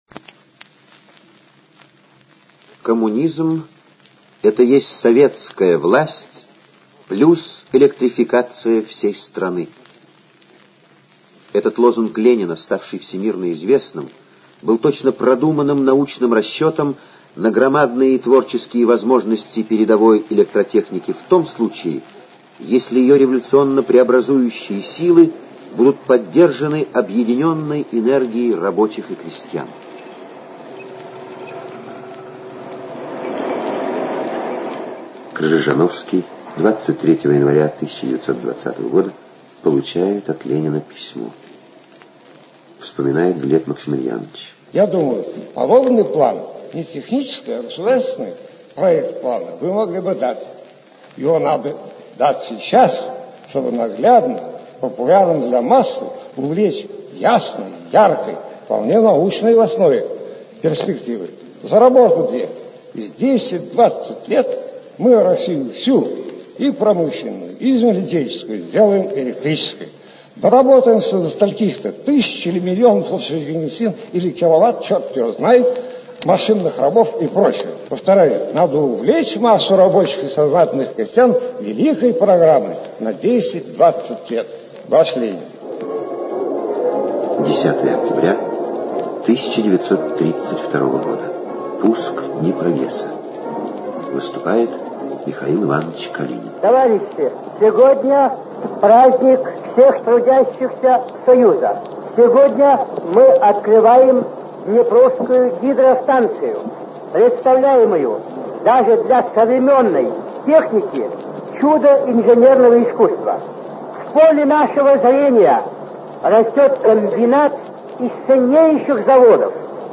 Описание: Звуковой плакат о реализации плана ГОЭЛРО.